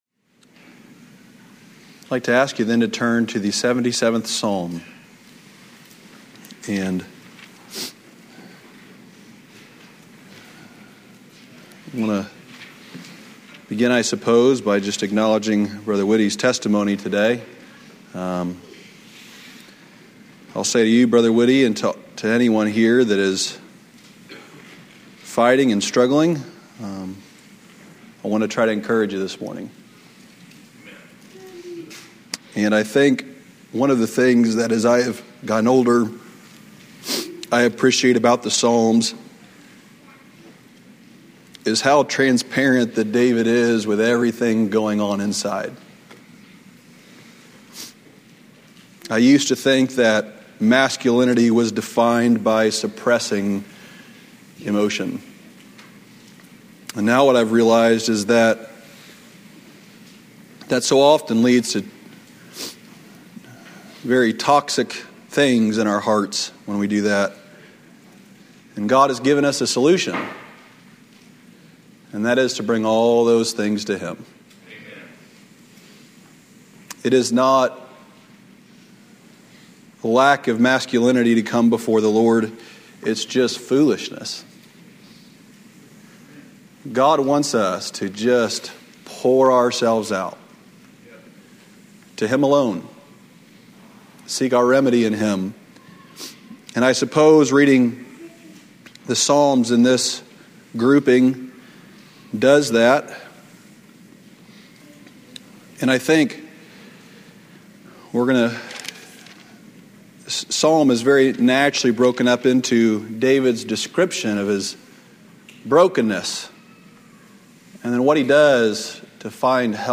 Sermons from our Sunday morning worship services.